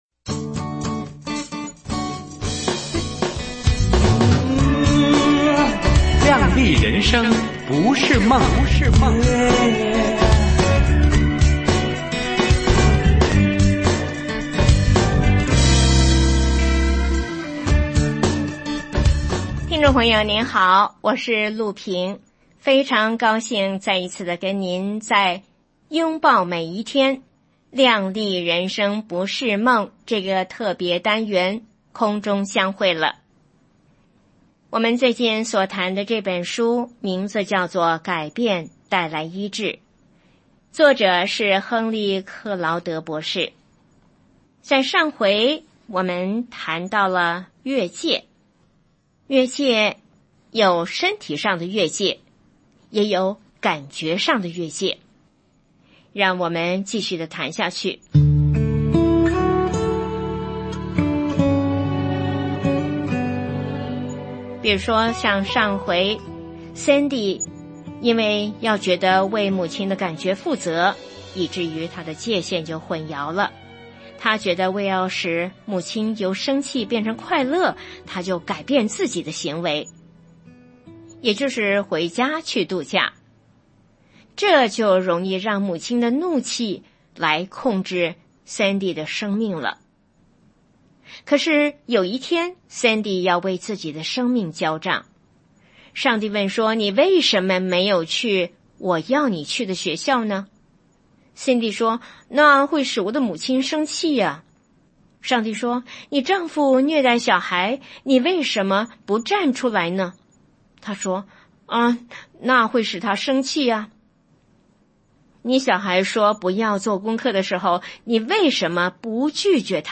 首页 > 有声书 > 灵性生活 > 改变带来医治 | 有声书 | 灵性生活 > 改变带来医治：30 越界